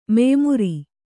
♪ meymuri